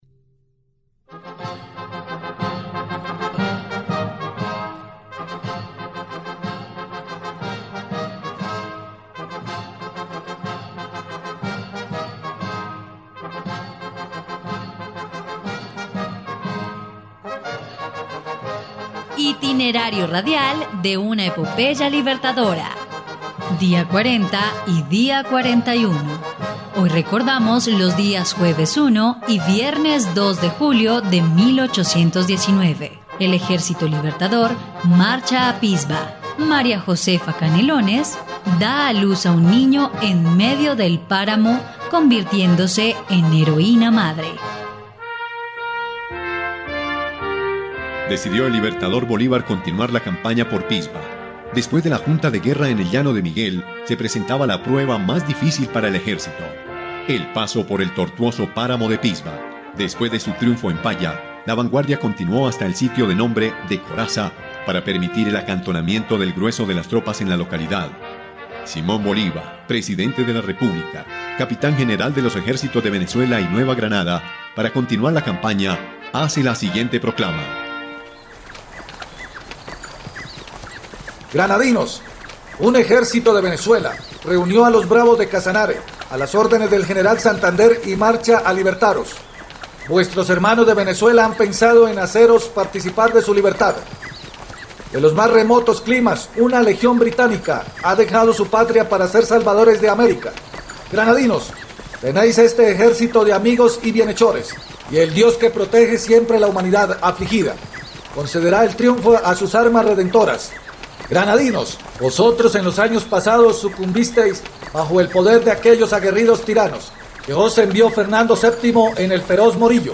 dia_40_y_41_radionovela_campana_libertadora.mp3